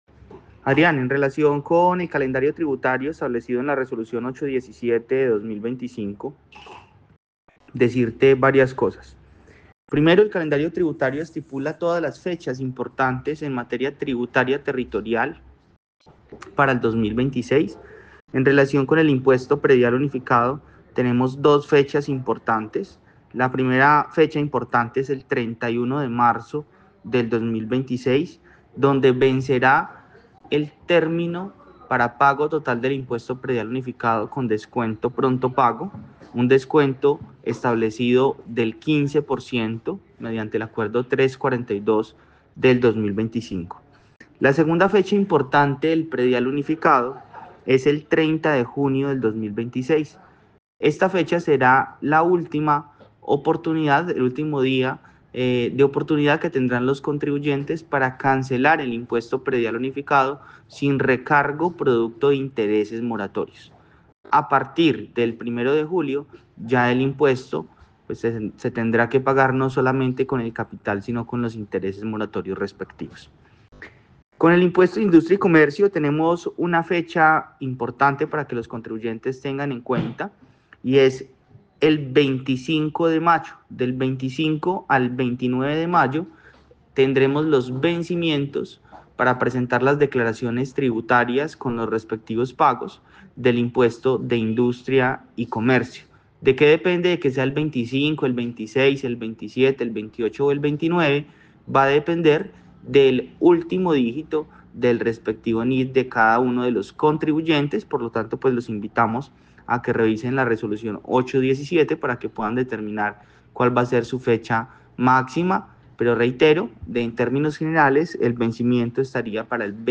Yeisón Pérez, secretario de hacienda de Armenia
En 6AM/W de Caracol Radio Armenia hablamos con el secretario de hacienda municipal, Yeisón Andrés Pérez sobre el calendario tributario para el pago de impuesto en la capital del Quindío en este 2026.